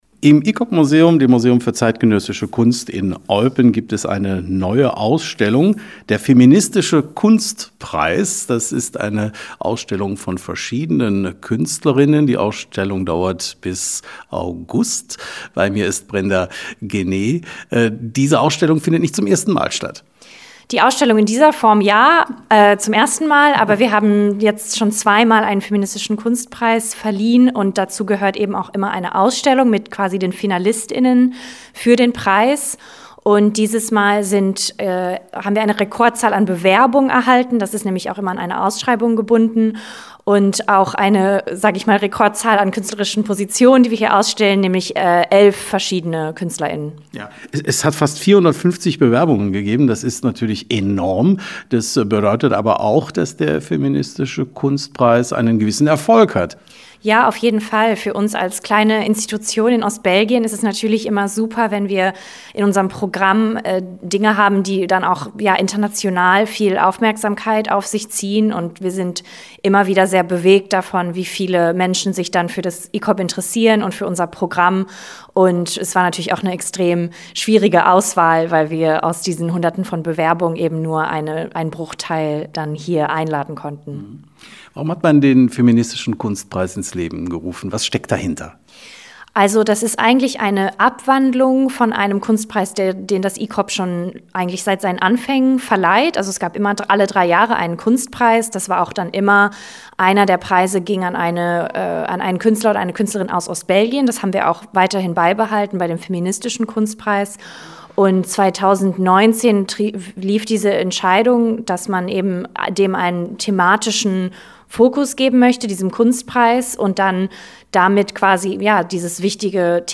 Radio Contact